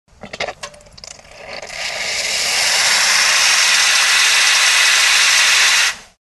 Звук бариста делает кофе на кофемашине